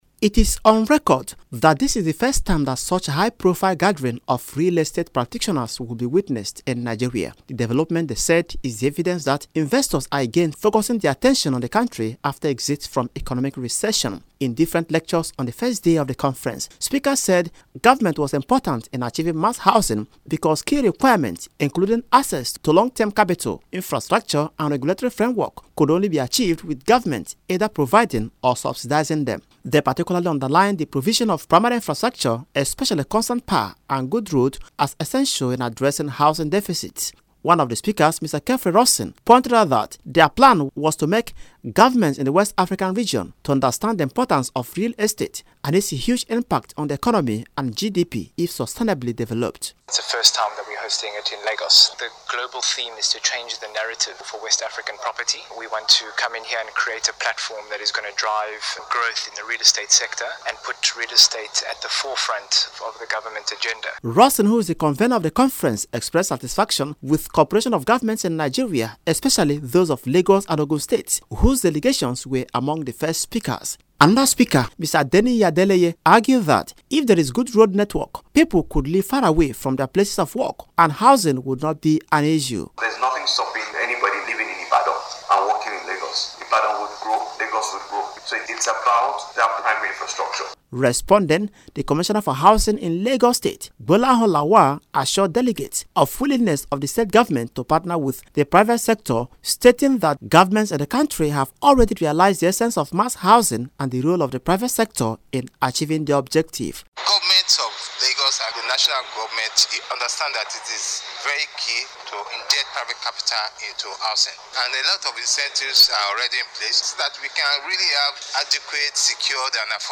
Radio Report: Government urged to prioritise real estate to actualise mass housing
This was the consensus of over 350 real estate practitioners from across the globe who are participants at a regional conference in Lagos, aimed at strengthening public and private partnership towards developing the real estate sector in West Africa.